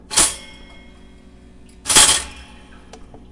烤面包机